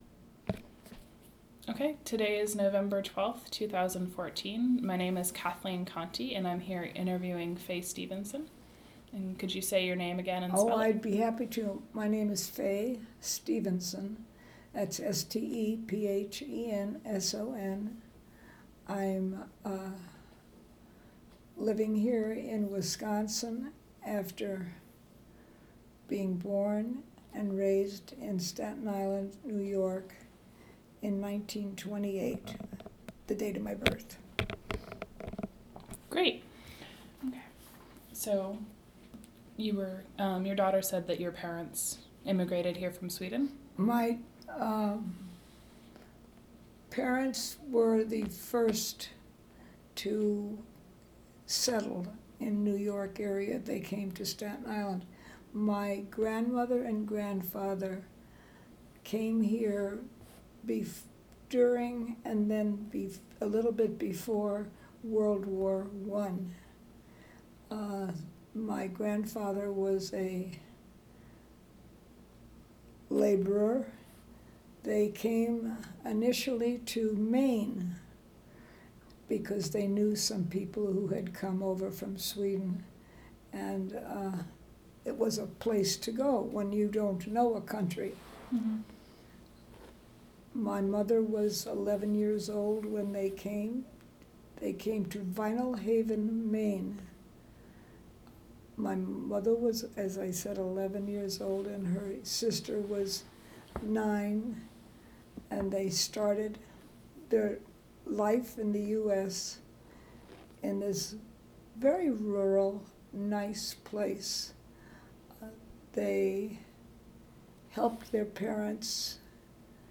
Oral History Interview
This interview was conducted for inclusion into the UW-Madison Oral History Program.